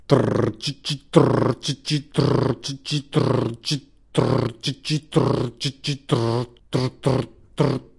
Vocals Short Sounds " Beatbox Boom Tsi Ke Ka
描述：一个人声的beatbox rhytmn。用Edirol R1 Sennheiser ME66.
标签： 节拍 节奏 口技 歌唱 口技 口技
声道立体声